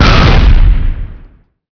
boil_explode.wav